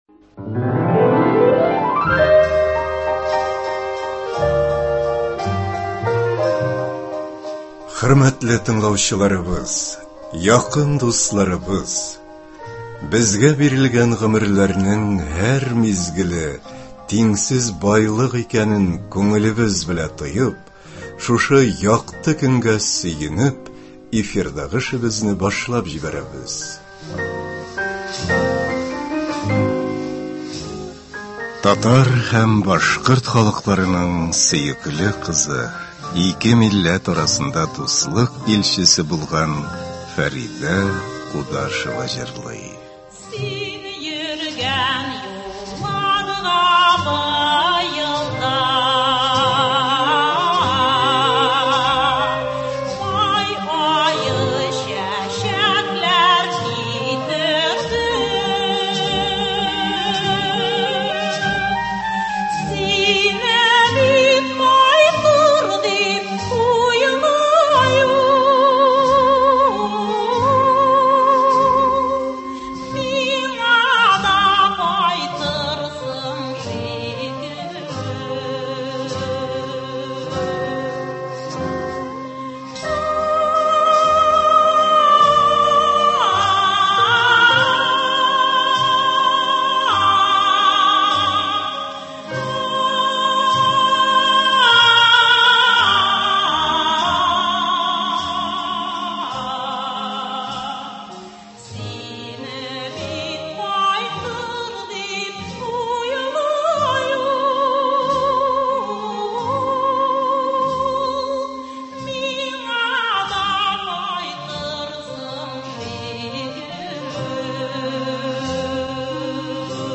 «Ак канат». Фәридә Кудашева иҗатына багышланган әдәби-музыкаль композиция.